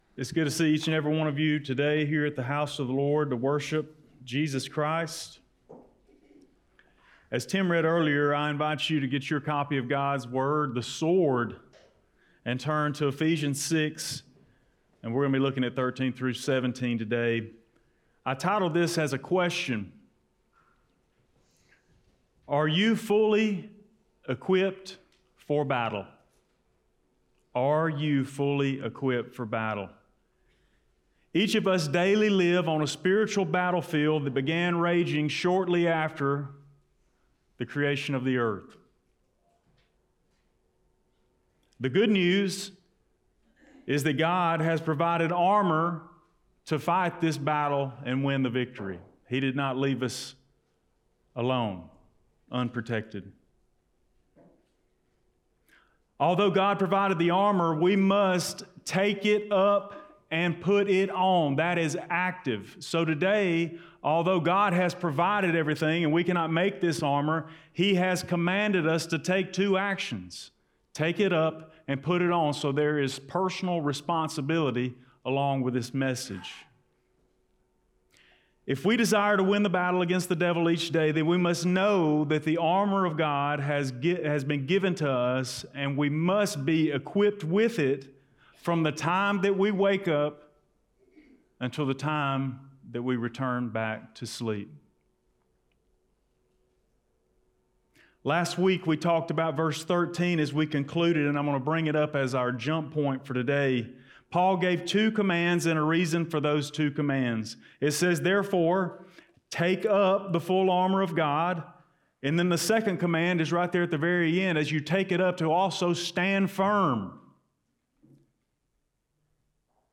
Blount Springs Baptist Church Sermons Are You Fully Equipped for Battle?